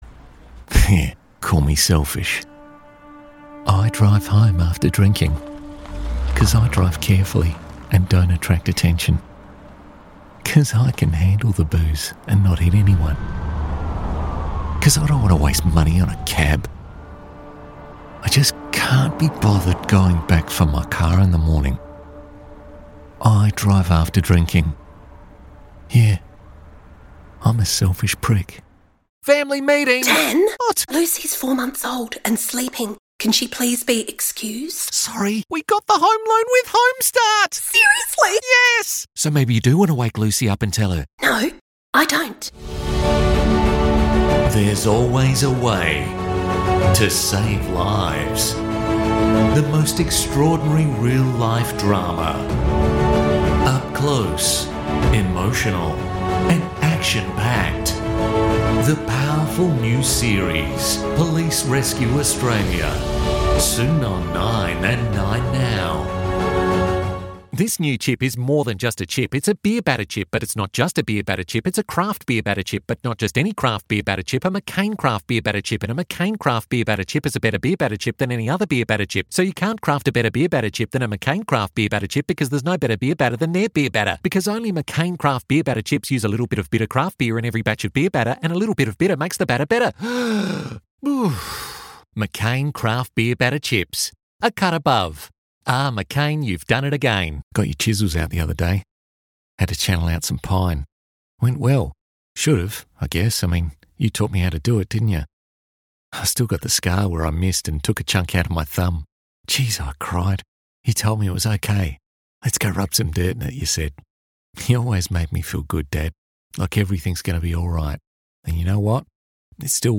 Demo
Adult, Mature Adult
Has Own Studio
australian | natural
ANIMATION 🎬